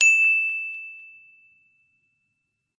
bell.wav